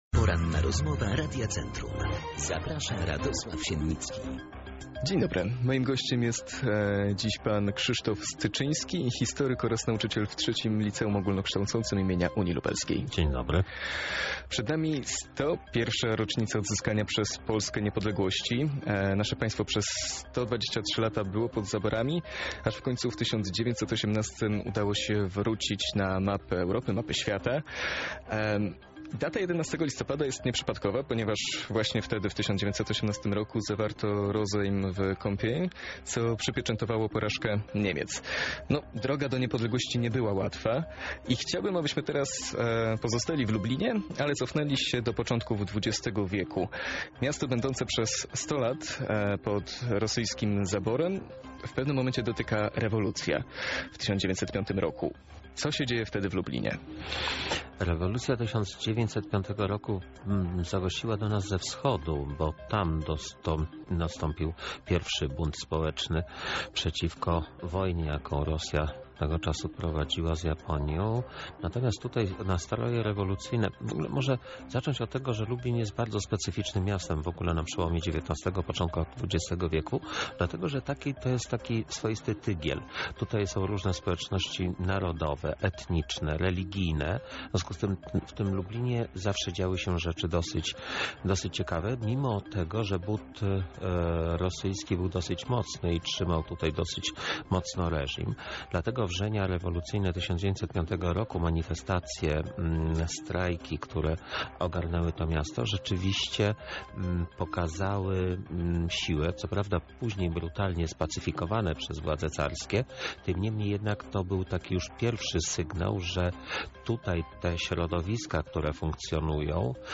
Pełna rozmowa dostępna poniżej: